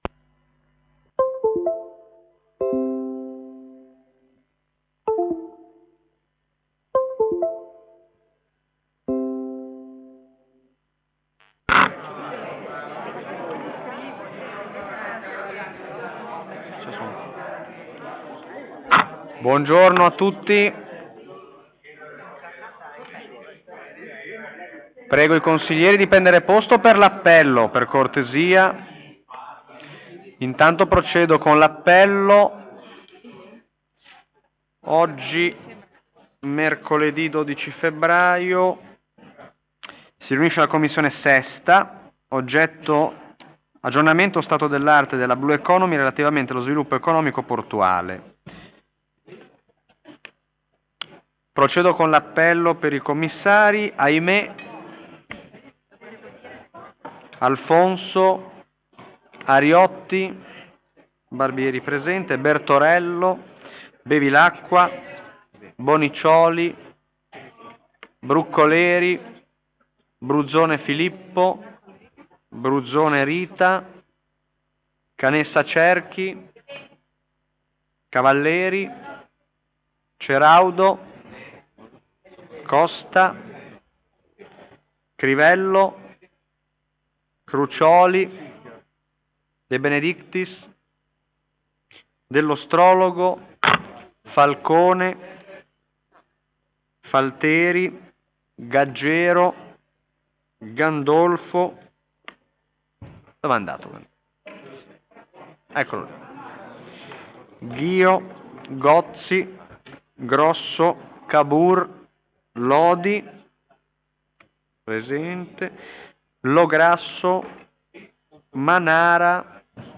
Luogo: presso la sala consiliare di Palazzo Tursi - Albini
Convocazioni: conv.comm_.cons_._vi_del_12.02.2025_ore_14.30.pdf Verbale discussione: verbale_sintetico.pdf Audio seduta: commissione_consiliare_vi_di_mercoledi_12_febbraio_2025_ore_1430.mp3